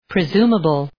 Προφορά
{prı’zu:məbəl}